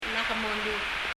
ちょっと録音状態がよくなくて聞き取りにくいです。
lak molub [lʌk mɔlub] コロールの街中を歩いていると、あちこちに 「LAK MOLUB」 という表示を見かけます。
最後の k はのどの奥のほうで 息をつめてて出す、いわゆる aspirate の k (だと思います）。